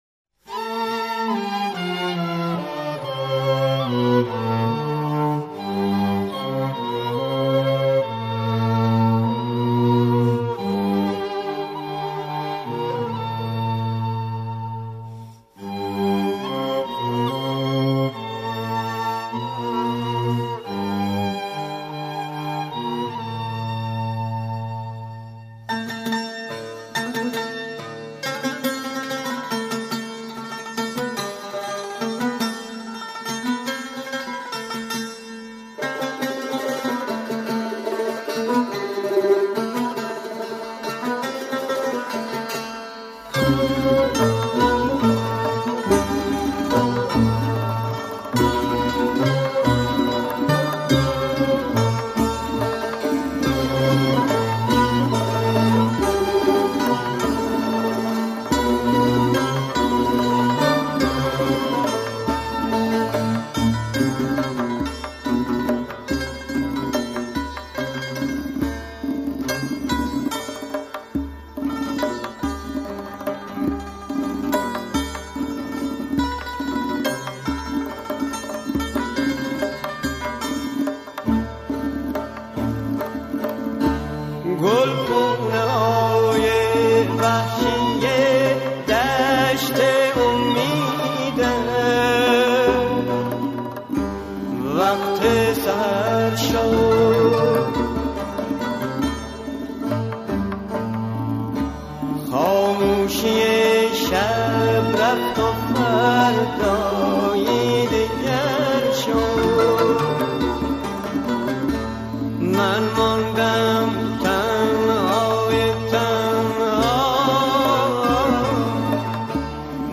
اهنگ غمگین